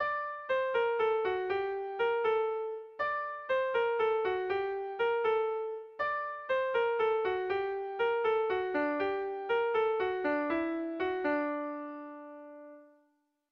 Air de bertsos - Voir fiche   Pour savoir plus sur cette section
Irrizkoa
AAB